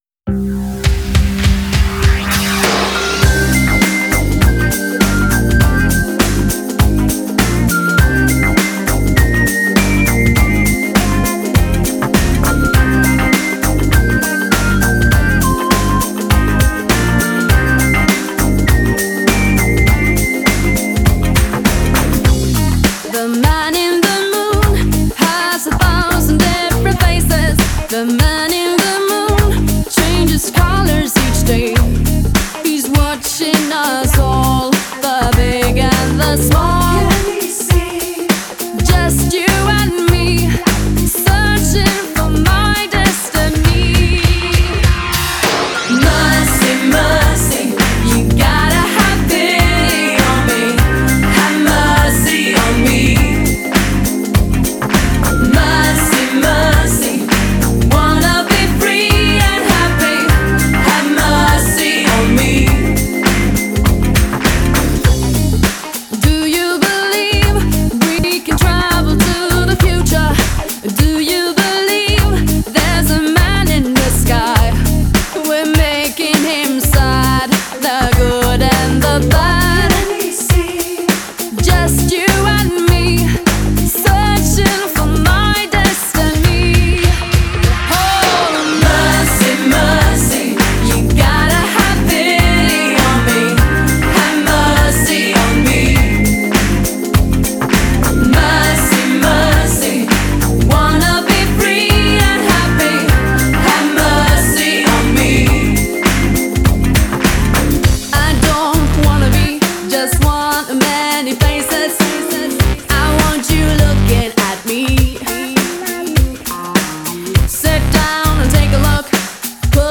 Genre: Electronic, Pop
Style: Eurodance